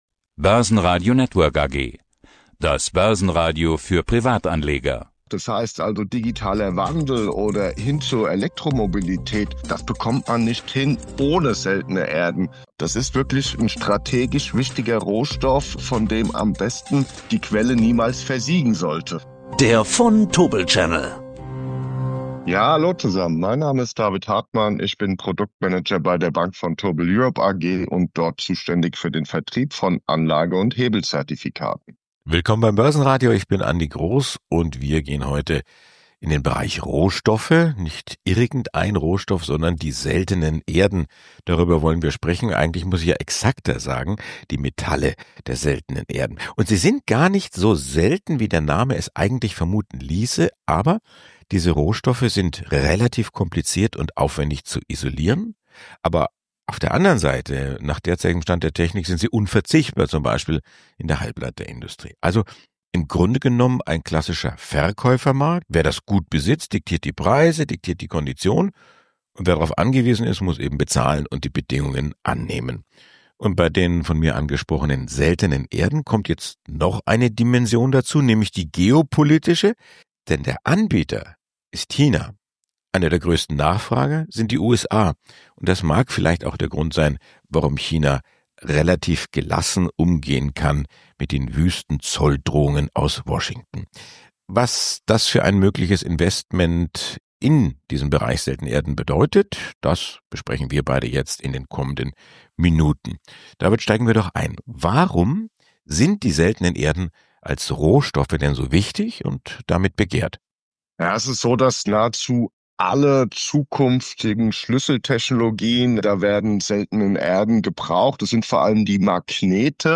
Ein Gespräch über Technologie, Abhängigkeiten und Investmentchancen in einem strategisch wichtigen Rohstoffmarkt.